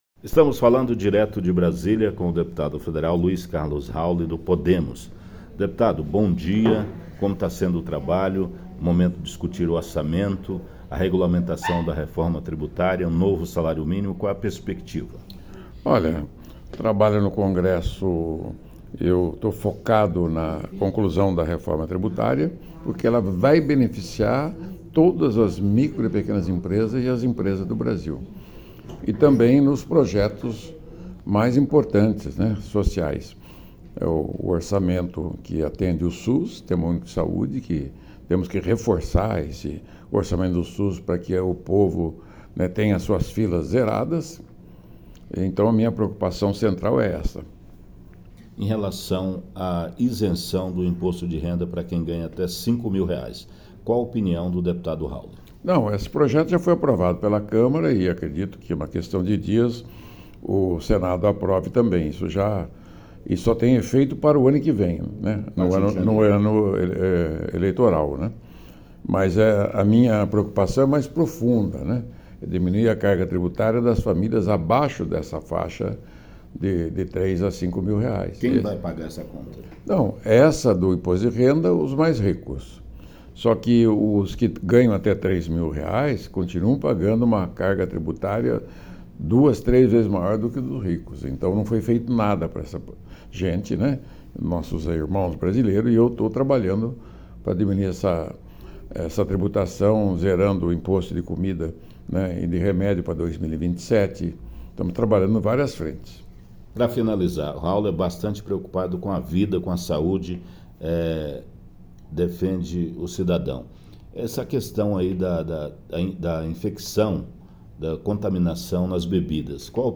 Direto de Brasília.